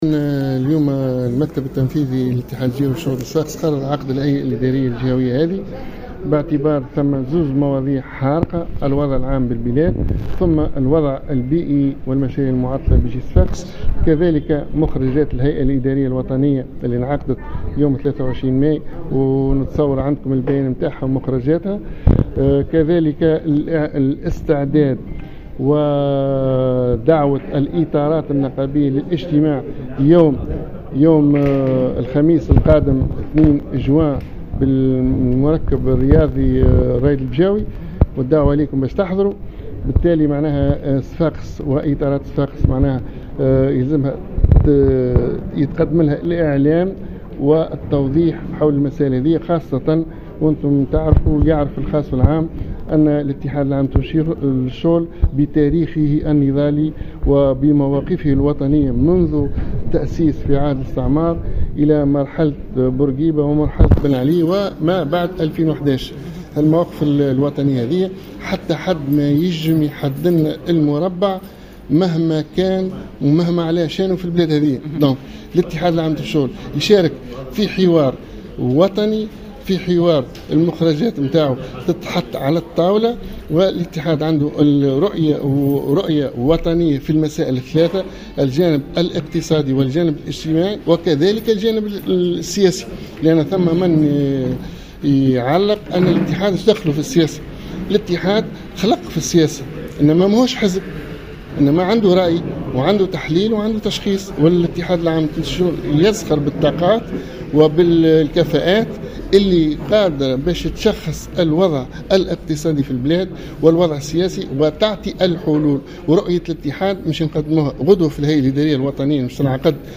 في تصريح لمراسلة الجوهرة أف أم